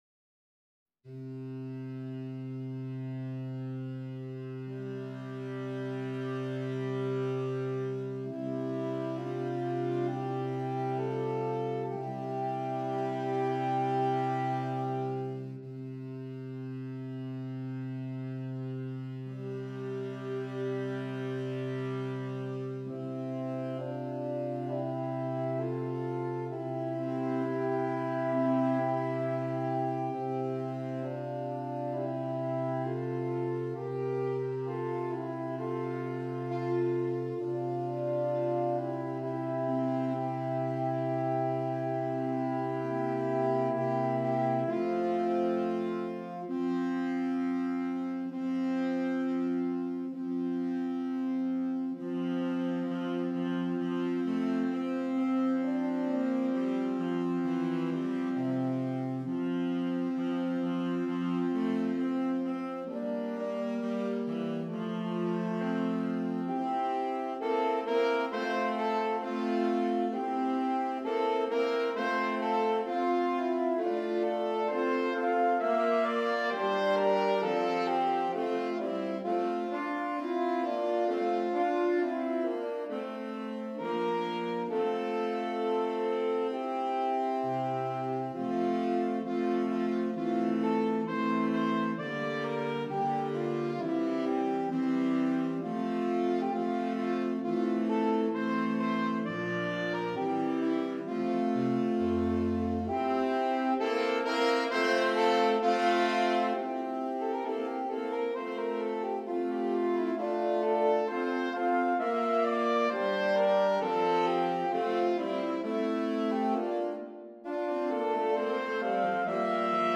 Saxophone Quartet (AATB)
Traditional English Folk Song